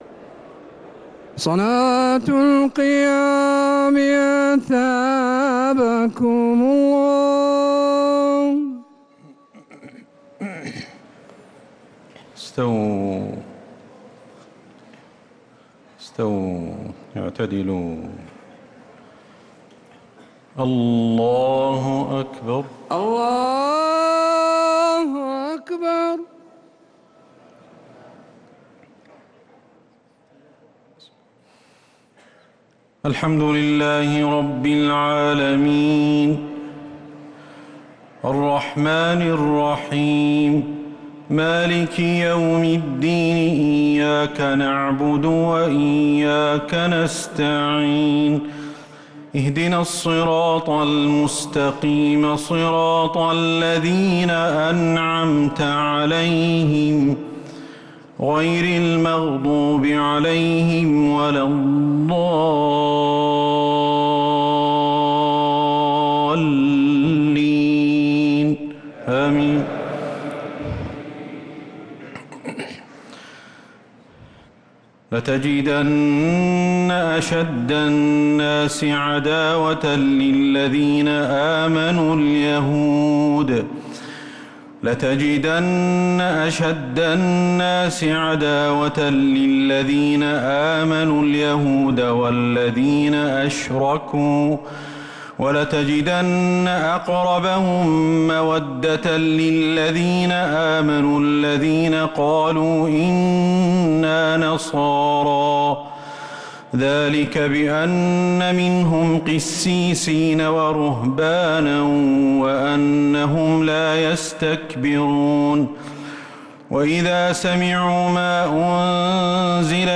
تراويح ليلة 9 رمضان 1446هـ من سورتي المائدة {82-120} و الأنعام {1-20} | Taraweeh 9th night Ramadan 1446H Surat Al-Ma'idah and Al-Ana'am > تراويح الحرم النبوي عام 1446 🕌 > التراويح - تلاوات الحرمين